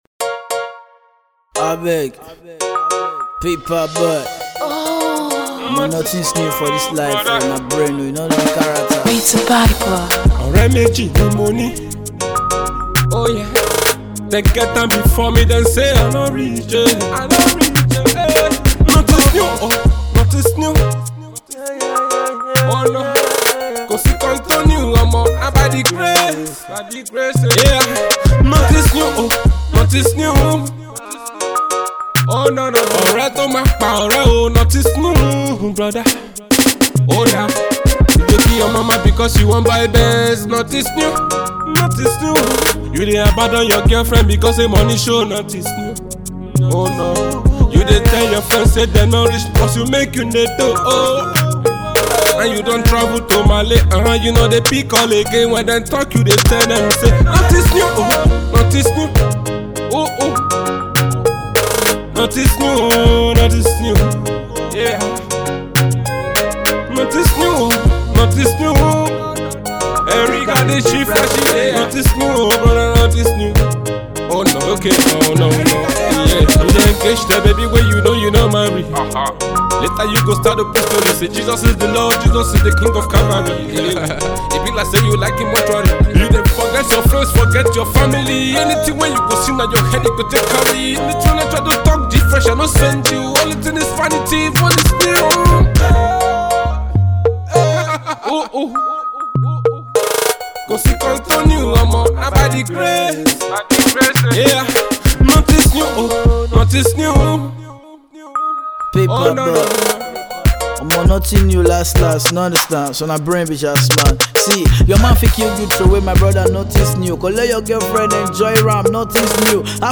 Afro-pop
Nigerian street-pop singer